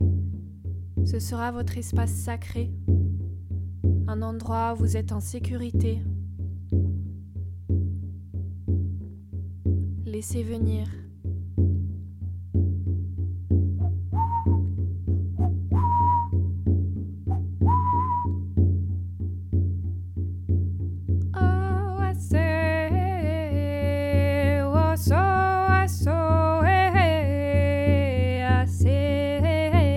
• Découvrir le rythme sacré du tambour, outil ancestral de connexion aux mondes subtils.
• Expérimenter la transe chamanique en toute sécurité, guidé(e) par le chant et les vibrations du tambour.